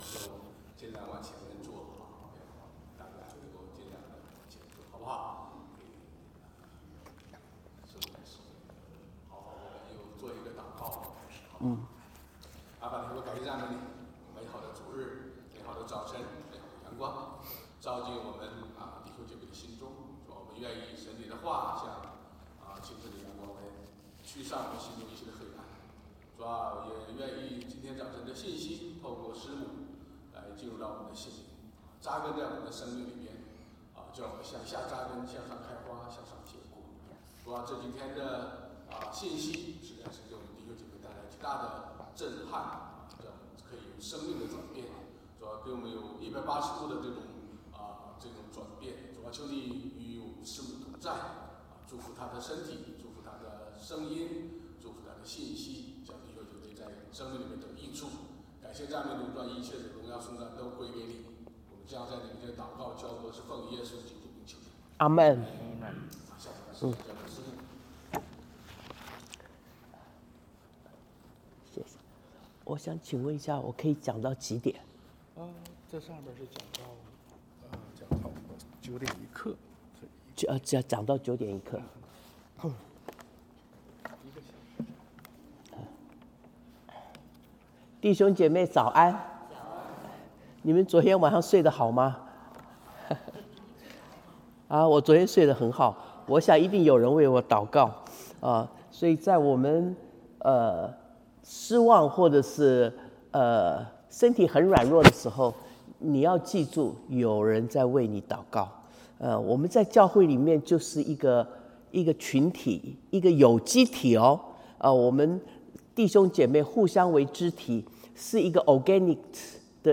Chinese Sermons | Chinese Christian Church of Greater Washington DC (en)